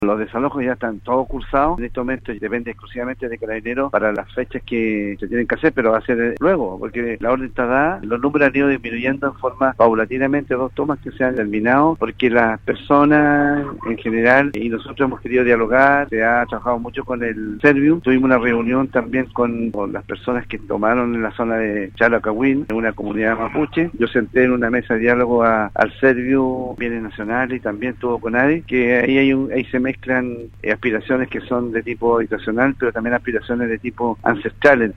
En conversación con Radio Sago, el gobernador provincial de Osorno, Daniel Lilayú, aclaró cómo se mantienen actualmente los respectivos desalojos de las tomas de terrenos en la ciudad.